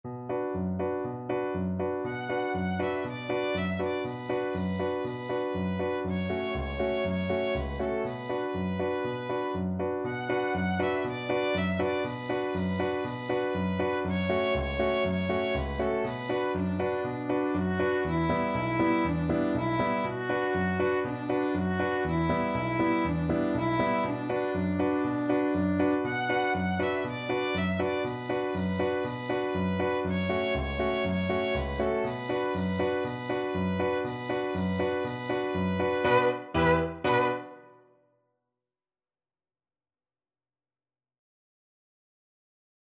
Allegro vivo (View more music marked Allegro)
4/4 (View more 4/4 Music)
Violin  (View more Easy Violin Music)
World (View more World Violin Music)